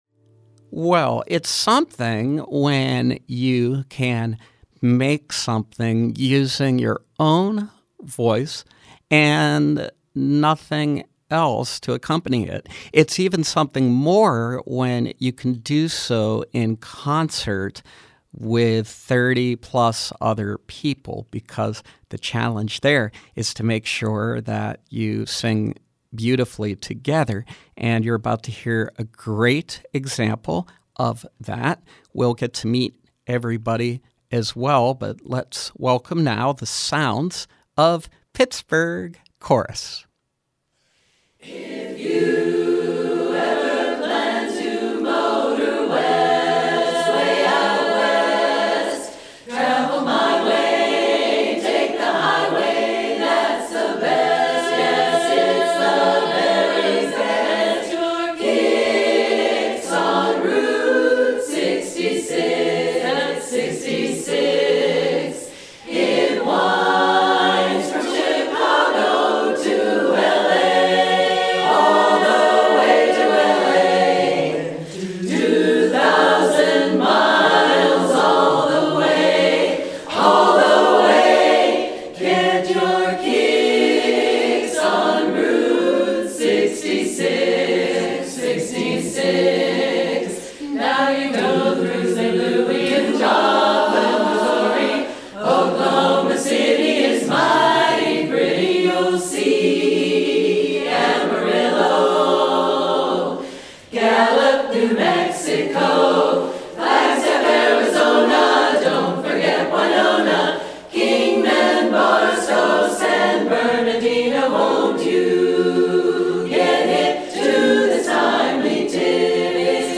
an award-winning women’s a cappella chorus